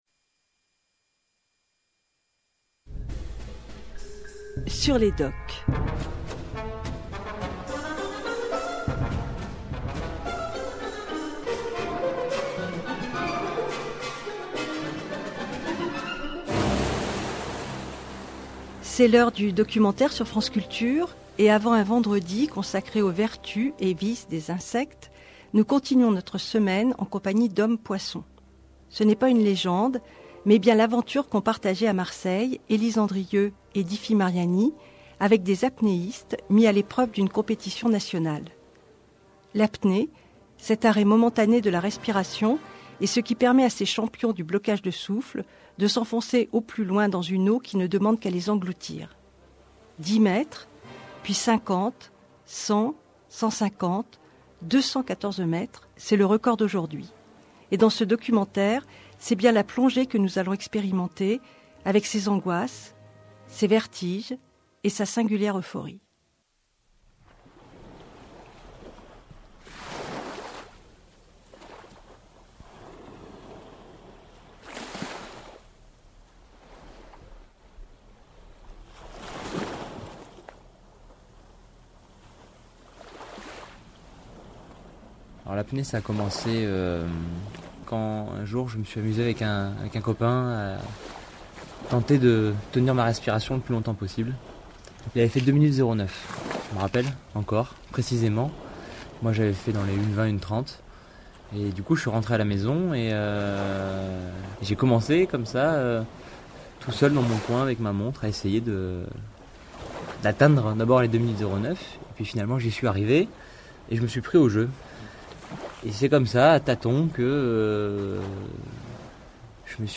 Re: Documentaire radiophonique "En apnée" !! le 6 juillet à 17h.
Ca dure 50 min, et ça met très bien dans l'ambiance...